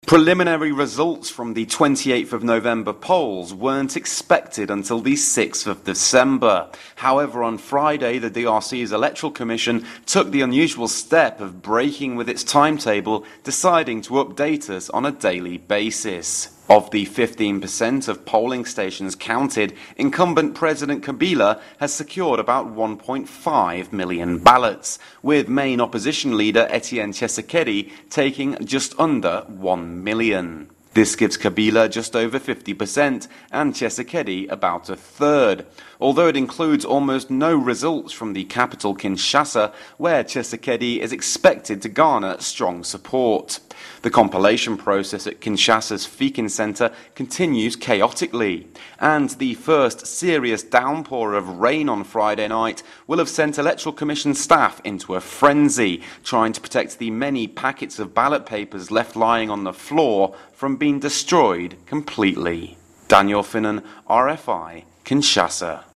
Report from Kinshasa